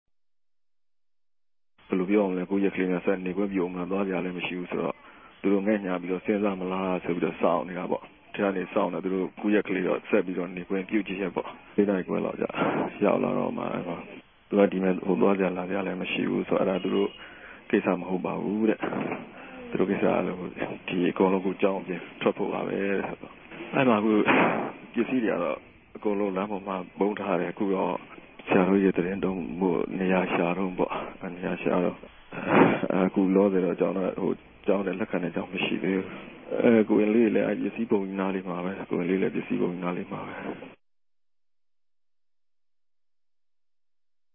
အဓမ္ထိံြင်ခဵ ခဵိပ်ပိတ်ခံရတဲ့ မဂ္ဂင်ကေဵာင်းက သံဃာတပၝးက RFAကို ူဖစ်ပဵက်ပုံ အေူခအနေ ေူပာူပတာကိုလည်း နားထောငိံိုင်ပၝတယ်။